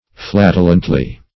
flatulently - definition of flatulently - synonyms, pronunciation, spelling from Free Dictionary Search Result for " flatulently" : The Collaborative International Dictionary of English v.0.48: Flatulently \Flat"u*lent*ly\, adv.
flatulently.mp3